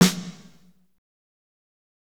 Index of /90_sSampleCDs/Northstar - Drumscapes Roland/DRM_AC Lite Jazz/SNR_A_C Snares x